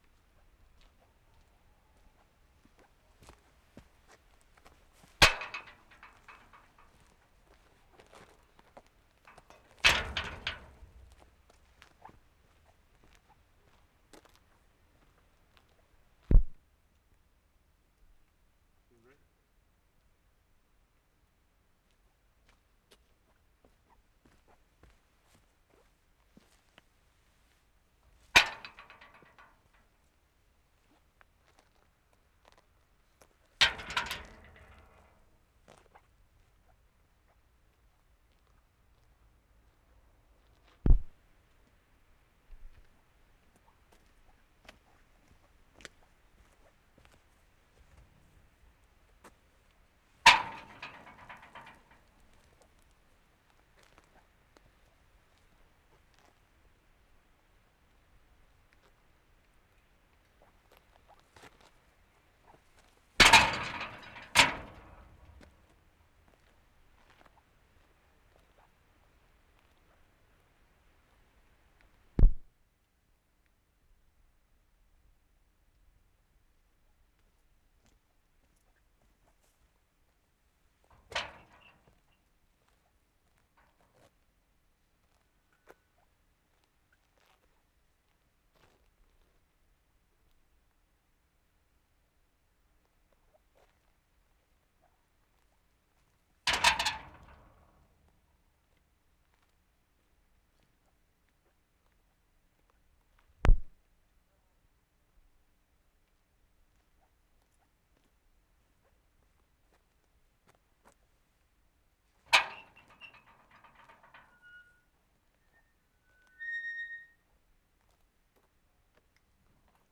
Wensleydale, England May 30/75
large metal driveway gate (6 subtakes).
Often the gate itself is quite silent, only the latch is heard opening and shutting. All examples are clear - the ambient level in each case is very low.